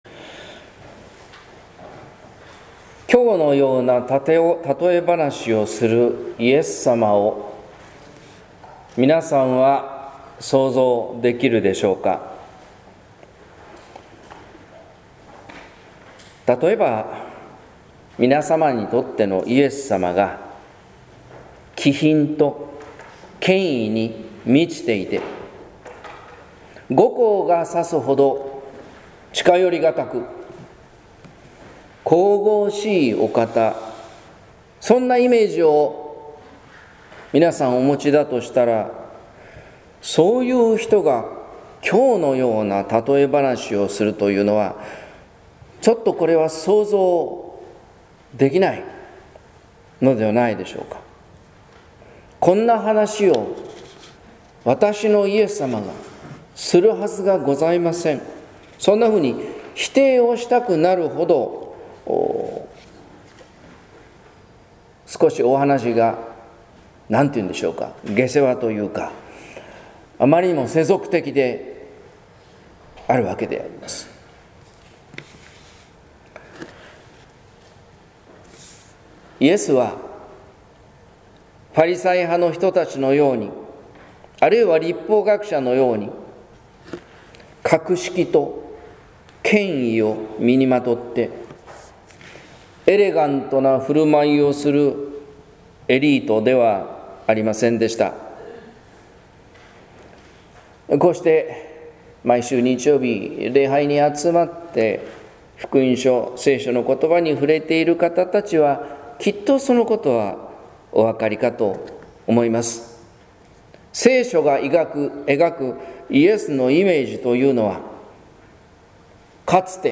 説教「コツコツ生きる幸せ」（音声版） | 日本福音ルーテル市ヶ谷教会